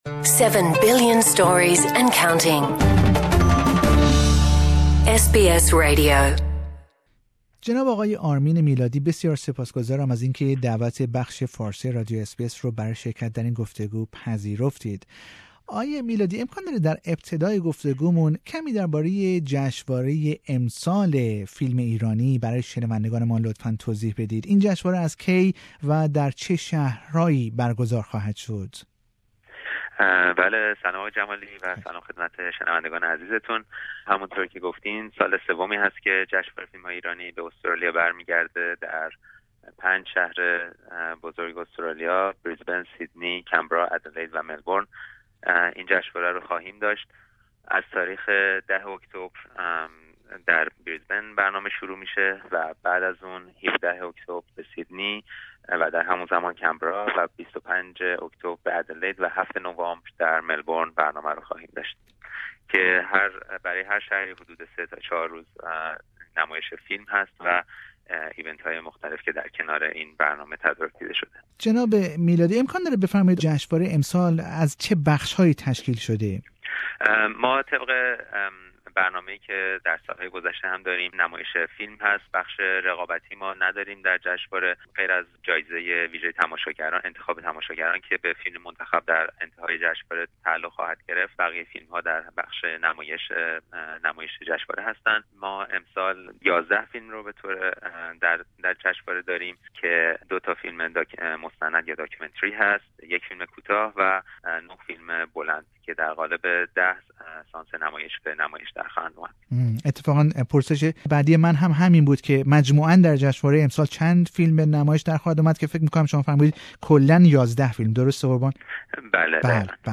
در گفتگو با بخش فارسی رادیو اس بی اس